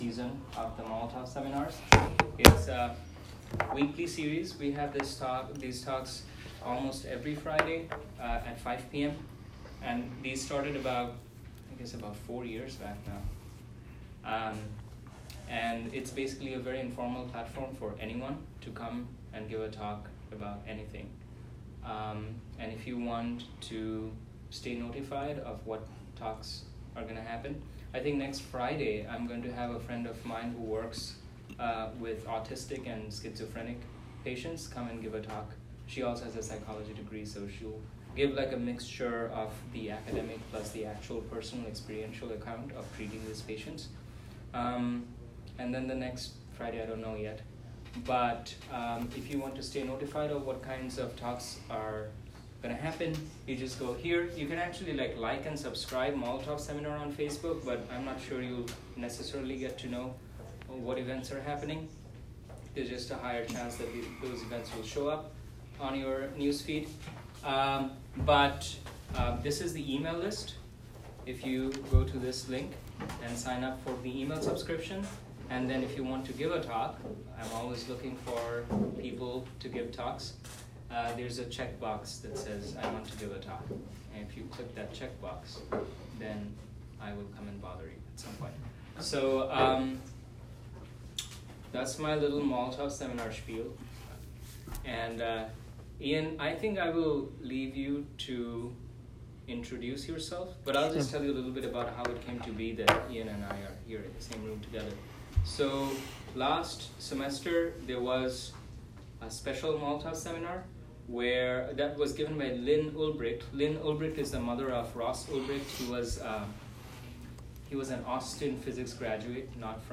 After the first 3 minutes where the Molotov Seminar is introduced, I spend 13 minutes giving my background story to provide enough useful context and then start answering questions for the next 70 minutes.